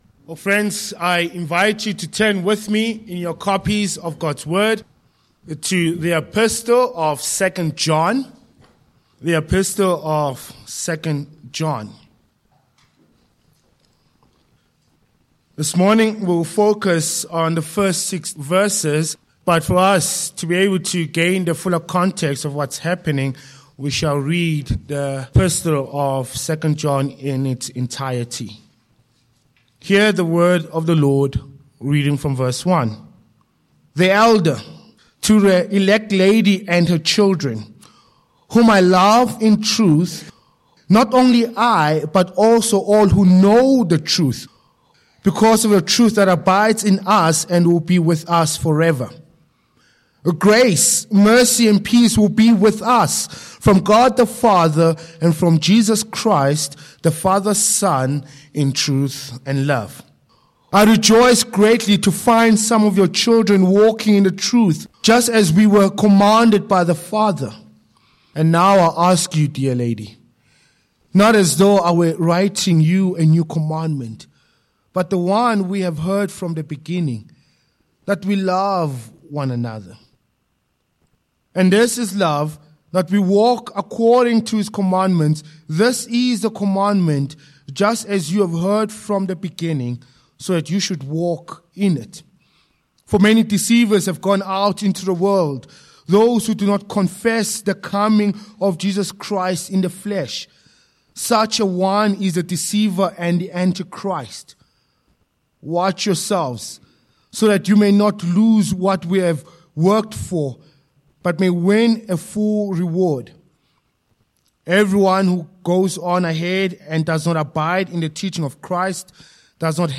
2 John 1-6 Service Type: Morning Passage